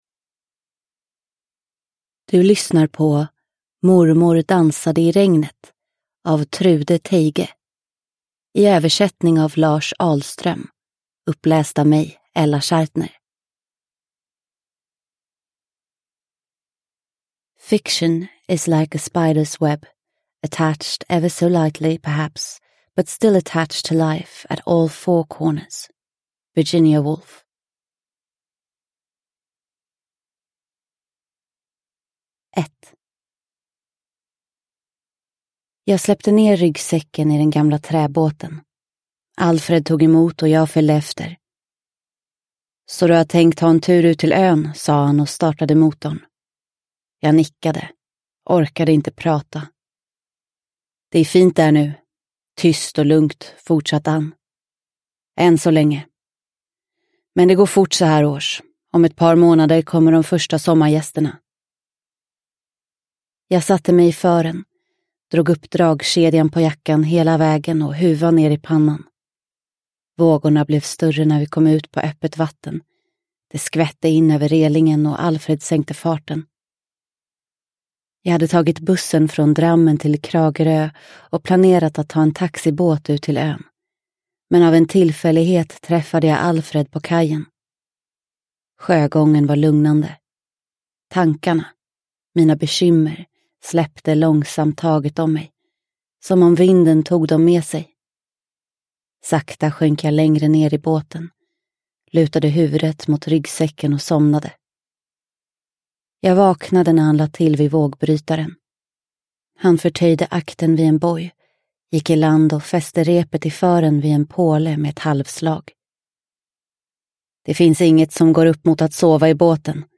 Mormor dansade i regnet – Ljudbok – Laddas ner